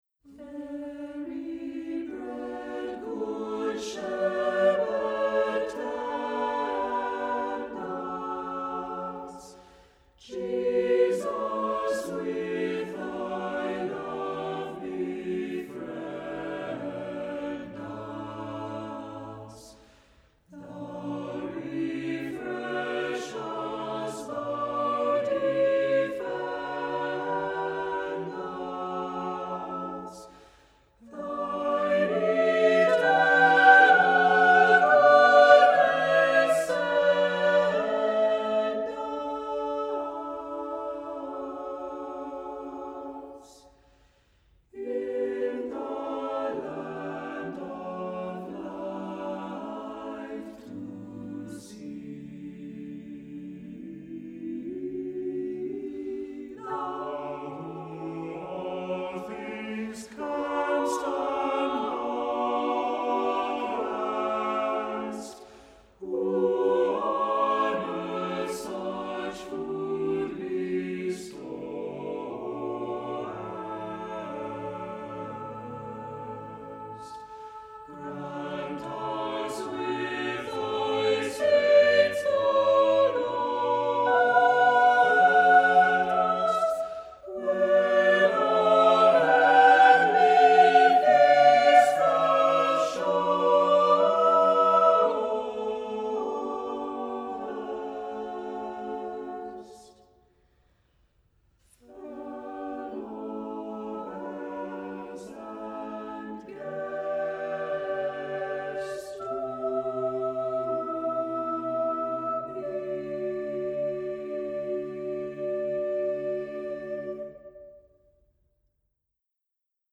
Accompaniment:      A Cappella
Music Category:      Christian